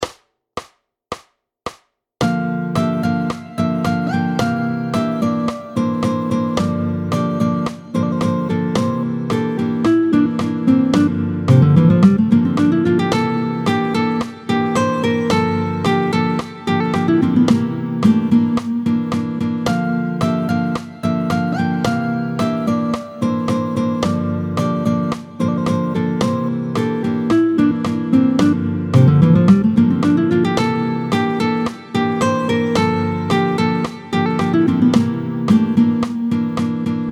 29-05 Le IIm7 / V9 en Fa. Vite, tempo 110